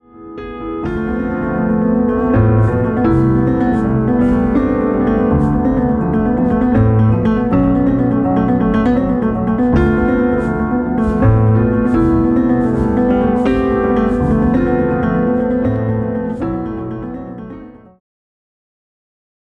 Electronic Organ
Kamanche